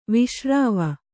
♪ viśrāva